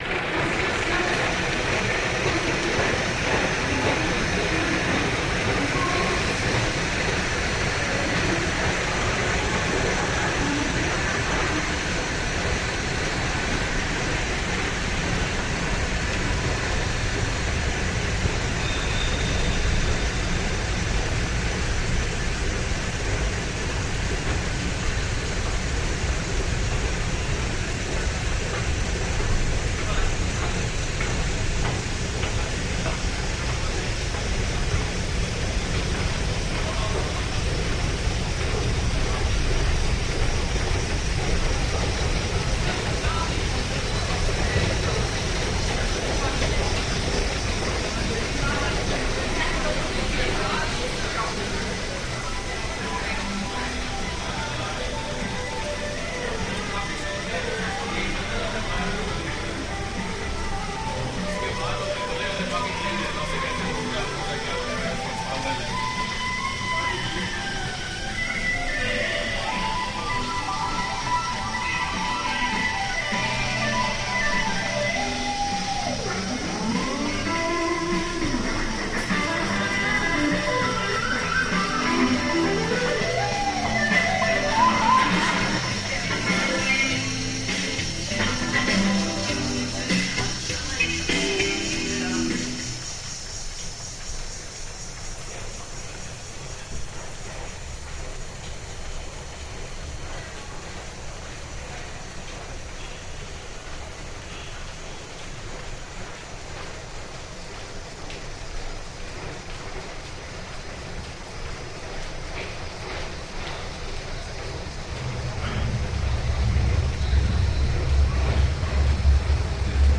Part of our project The Next Station, reimagining the sounds of the London Underground and creating the first ever tube sound map.